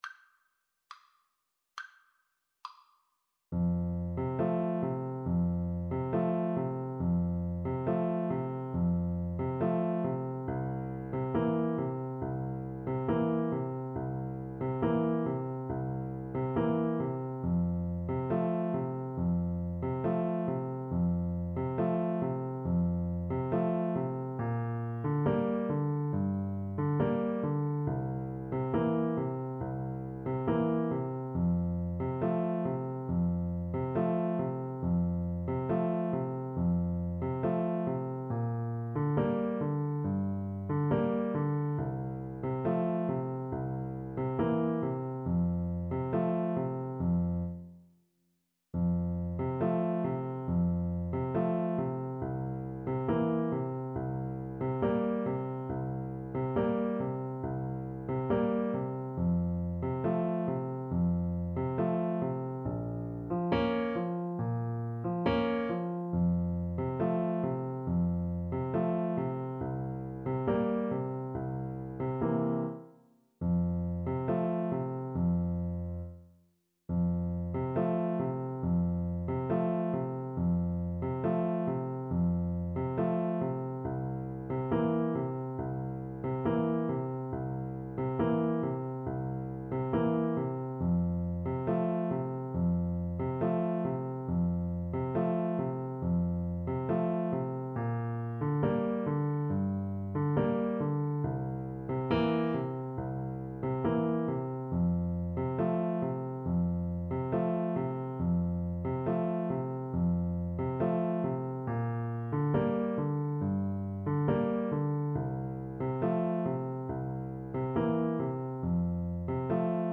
Piano Four Hands (Piano Duet)
2/4 (View more 2/4 Music)
Andantino = c. 69 (View more music marked Andantino)
Classical (View more Classical Piano Duet Music)